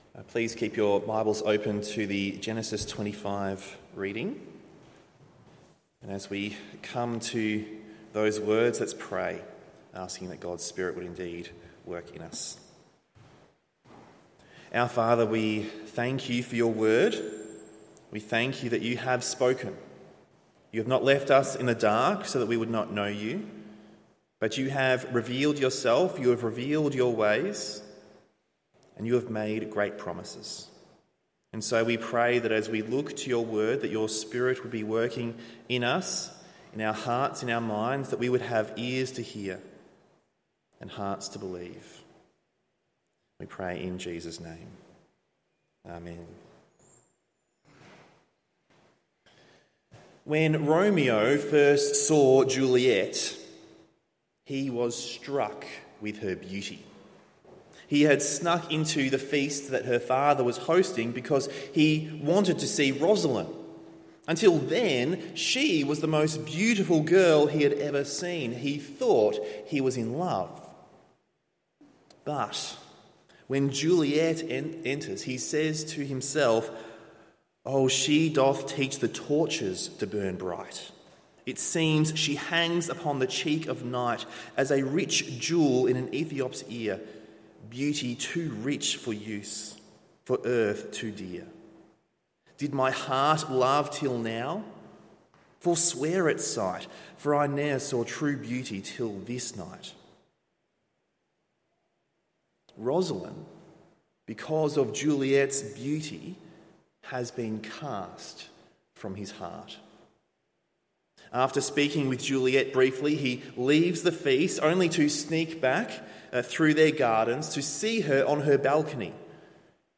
Morning Service Genesis 25:19-34…